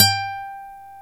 Index of /90_sSampleCDs/Roland L-CD701/GTR_Nylon String/GTR_Classical